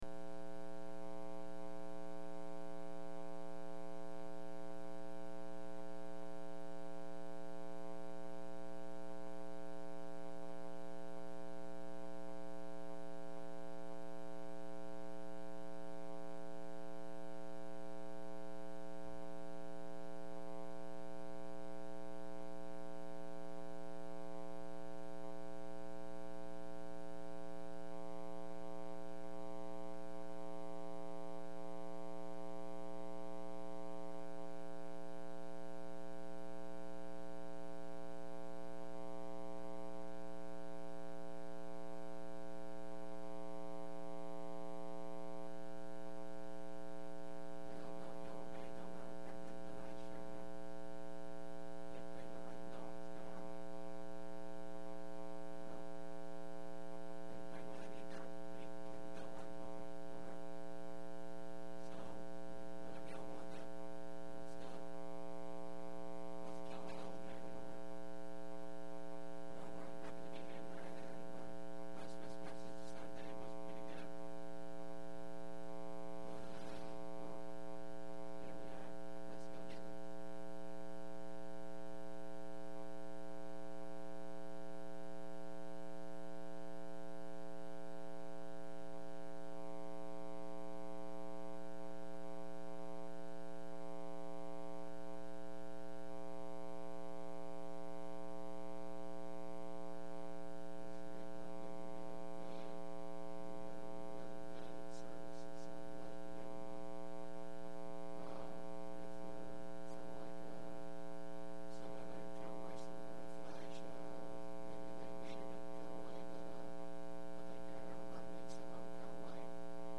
4/28/10 Wednesday Service